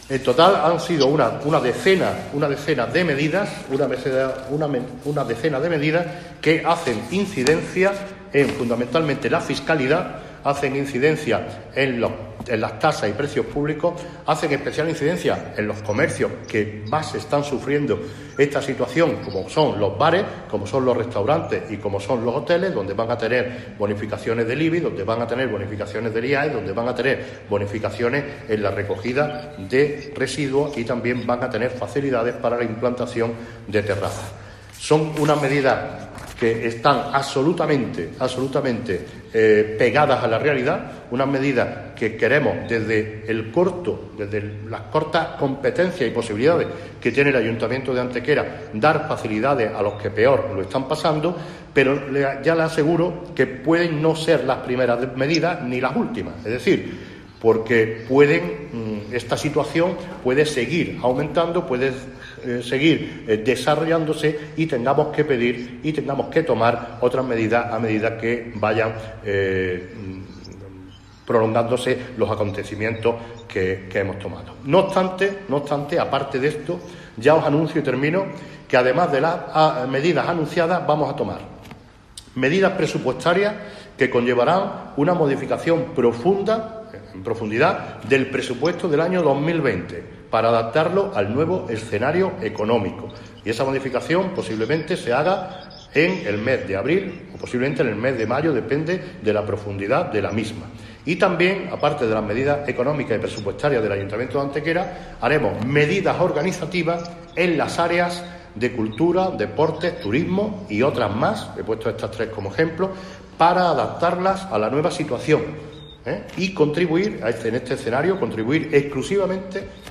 Cortes de voz
Corte de audio del alcalde Manolo Barón sobre las medidas fiscales propuestas   868.03 kb  Formato:  mp3